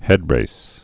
(hĕdrās)